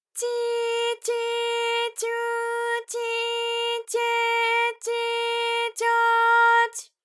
ALYS-DB-001-JPN - First Japanese UTAU vocal library of ALYS.
tyi_tyi_tyu_tyi_tye_tyi_tyo_ty.wav